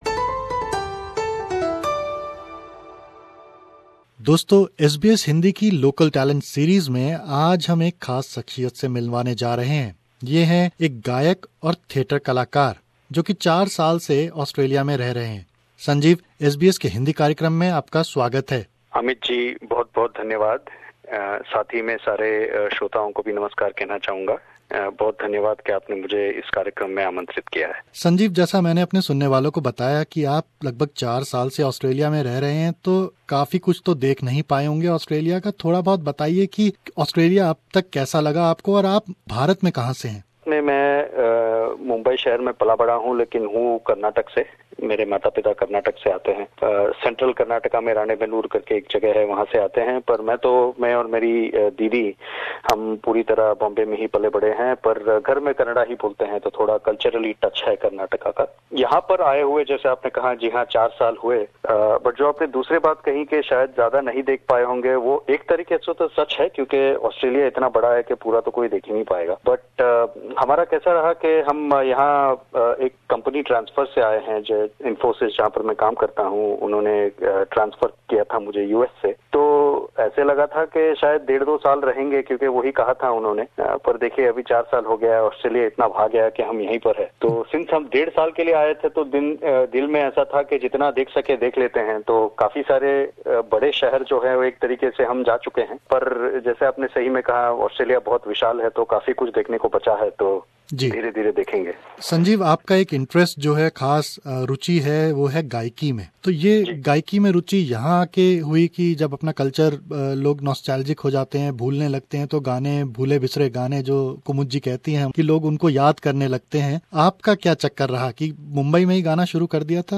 भेटवार्ता